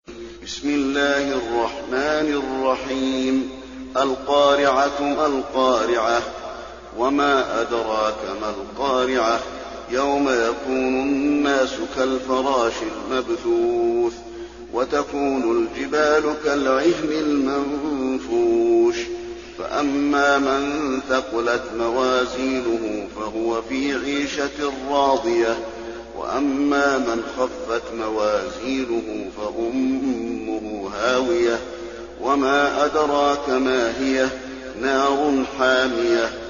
المكان: المسجد النبوي القارعة